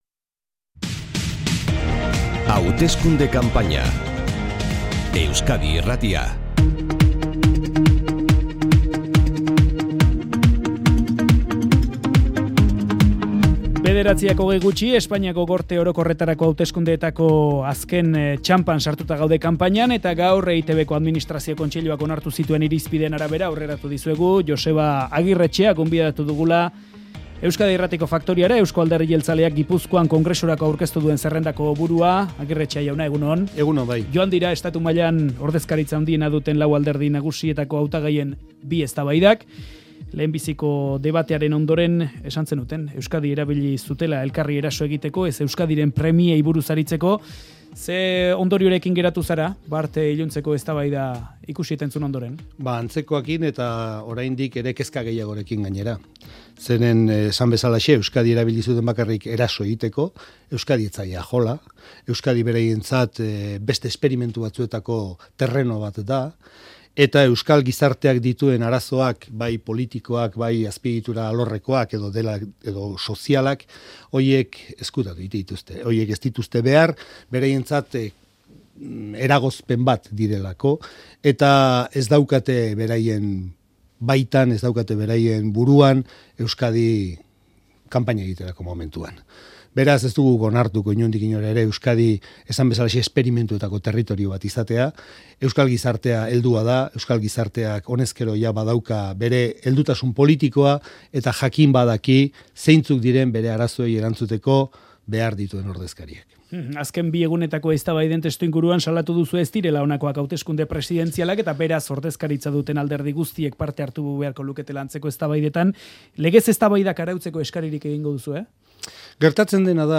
Hauteskunde elkarrizketa: EAJ